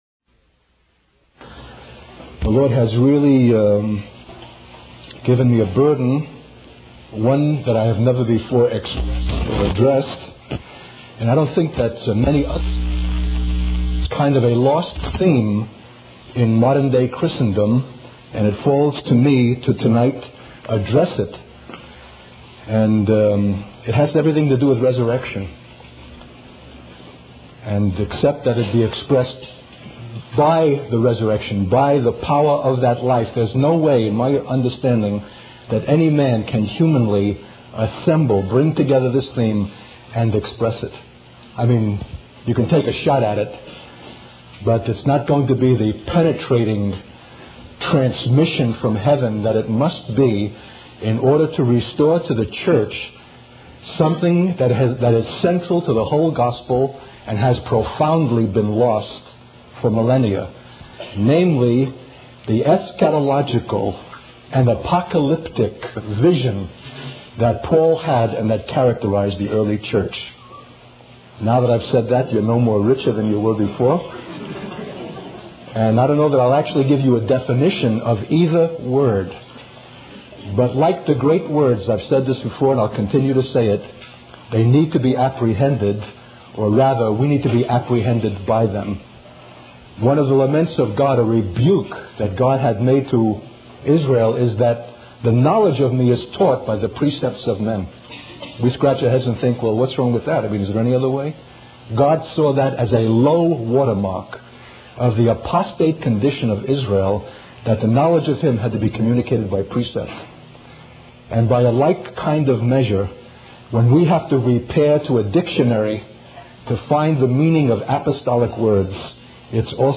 In this sermon, the preacher emphasizes the urgency of the gospel message and the imminent judgment of God. He challenges the idea that the gospel is simply a pleasant and soothing message, asserting that it is actually the apocalyptic heart of God's plan for restoration.